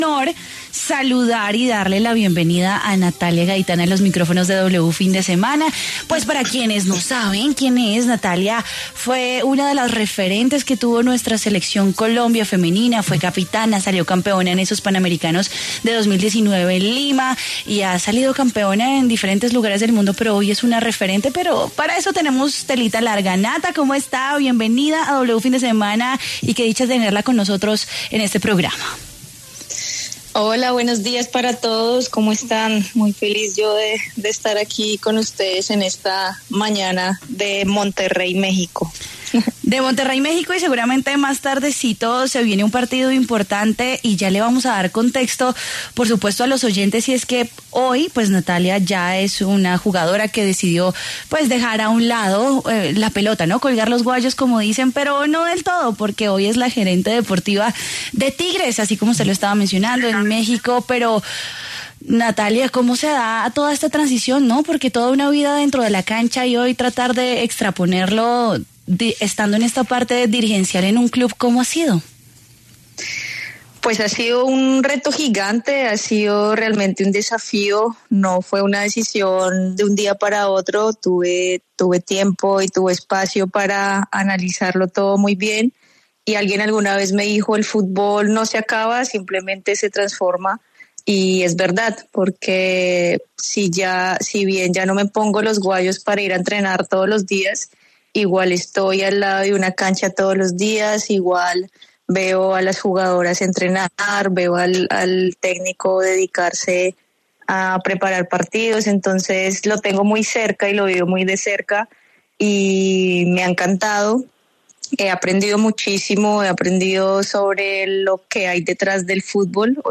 Natalia Gaitán, una de las referentes históricas, excapitana de la Selección Colombia Femenina y campeona panamericana, pasó por los micrófonos de W Radio Fin de Semana para hablar sobre su nueva vida después del fútbol profesional.